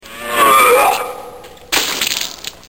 Tags: grindcore comedy horror gore insult